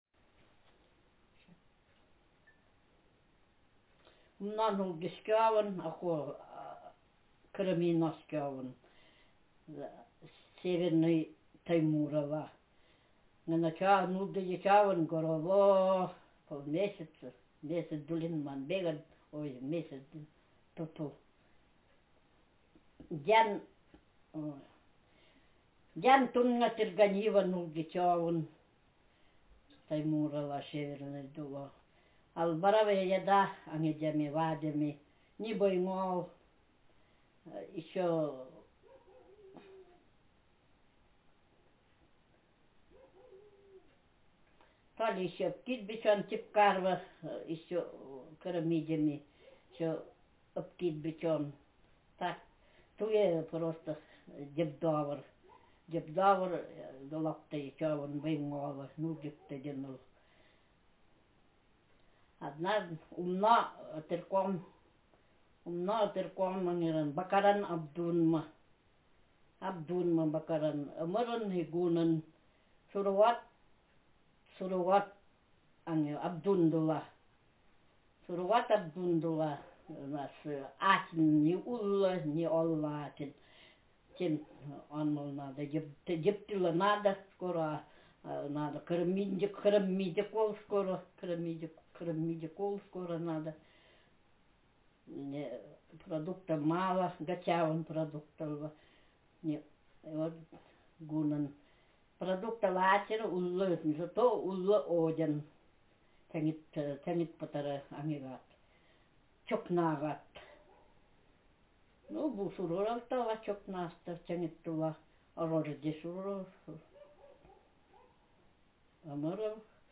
Speaker sexf
Text genrepersonal narrative